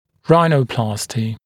[ˈraɪnə(u)ˌplɑːsti][ˈрайно(у)ˌпла:сти]ринопластика